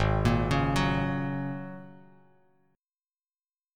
G#9sus4 chord